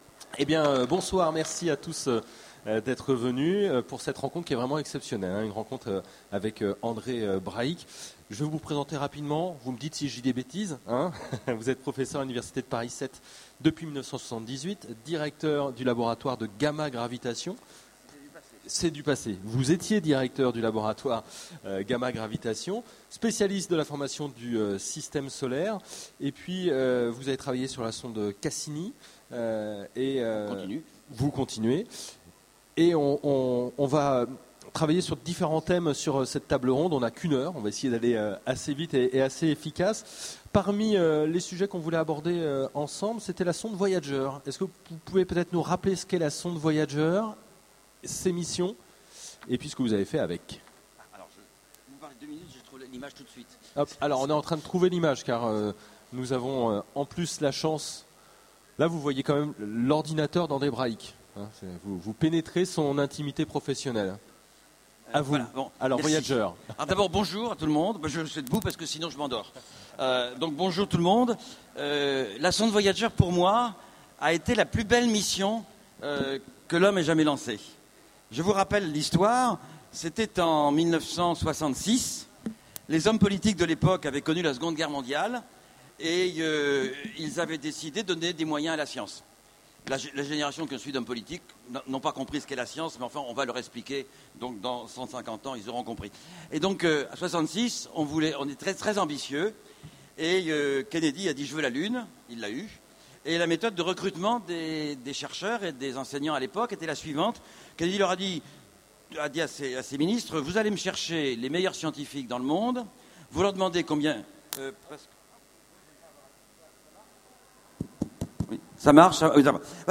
Utopiales 13 : Conférence Rencontre avec André Brahic
- le 31/10/2017 Partager Commenter Utopiales 13 : Conférence Rencontre avec André Brahic Télécharger le MP3 à lire aussi André Brahic Genres / Mots-clés Rencontre avec un auteur Conférence Partager cet article